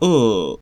43 ʊ vowel near-close near-back rounded [
near-close_near-back_rounded_vowel.wav